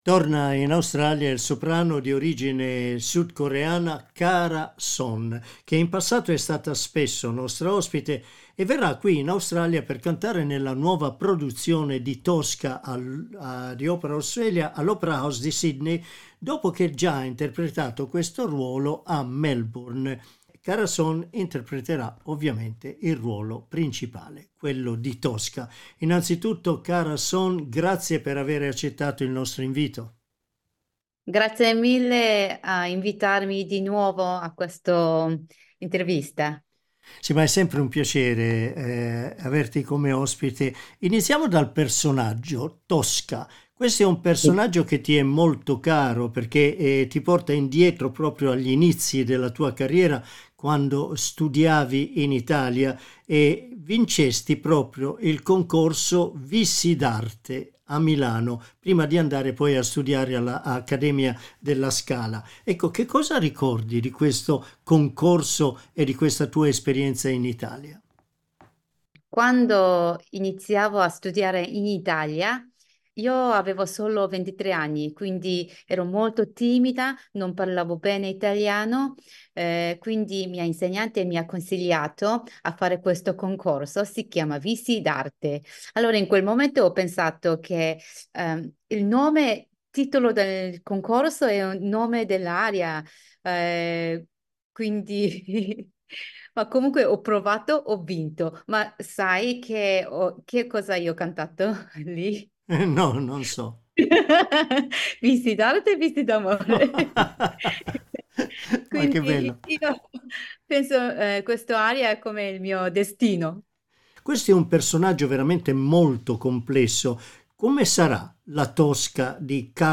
Clicca sul tasto 'play' in alto per ascoltare l'intervista Vissi d'arte è il mio destino.